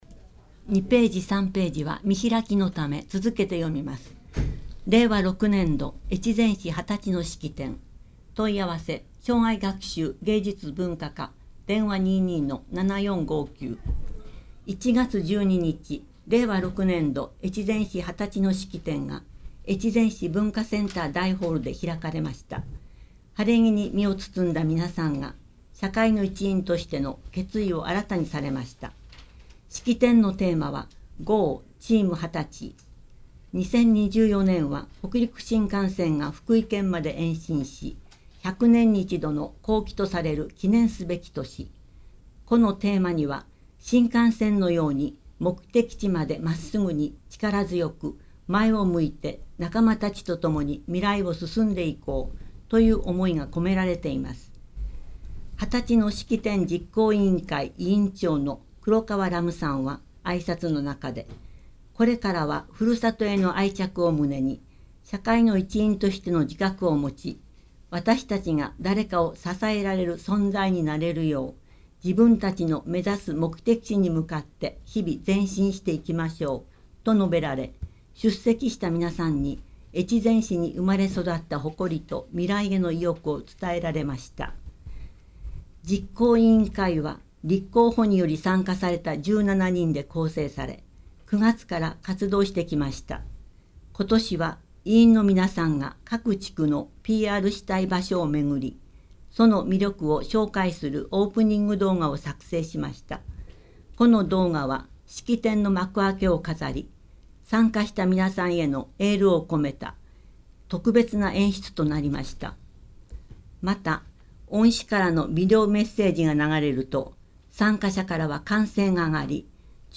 越前市広報2月号（音訳）
※越前市広報の音訳は音訳ボランティア「きくの会」の皆さんのご協力のもと配信しています。